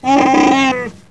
Grunts4 (25 kb)